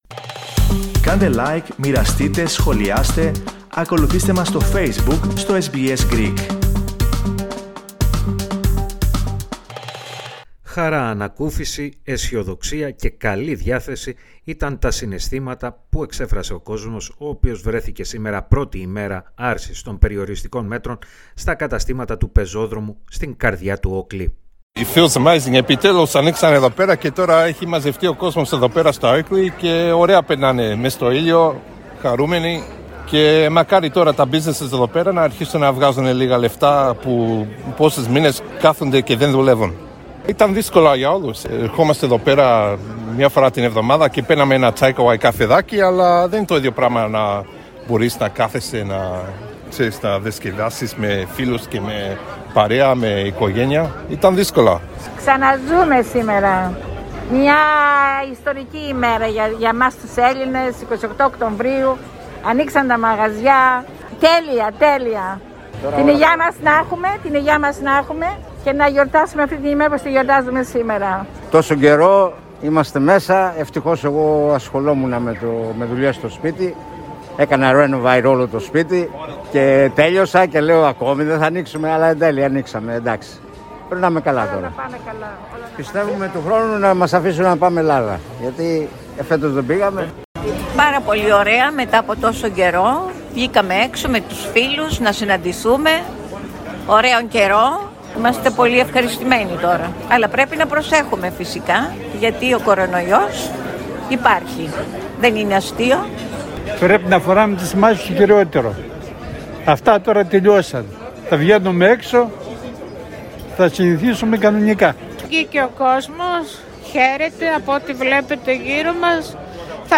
reportaz_okli_podcast.mp3